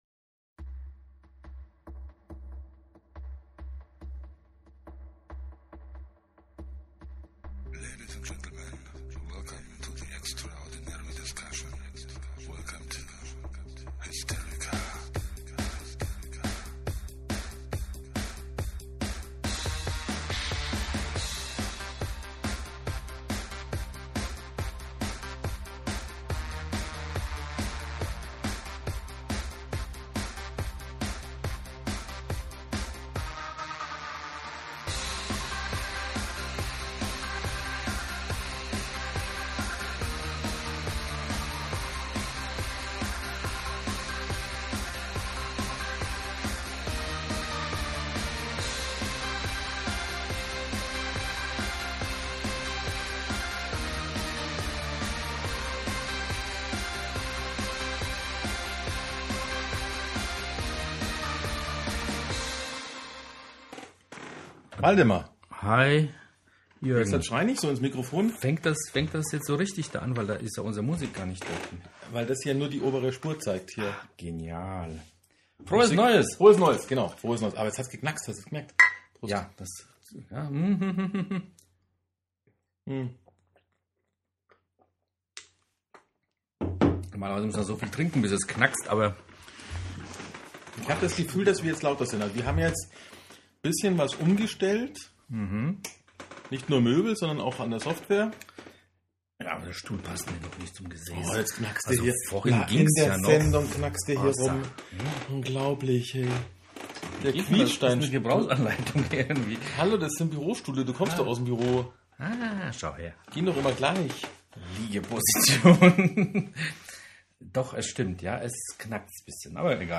Dabei schwankten sie von heiter zu ernst und wieder zurück.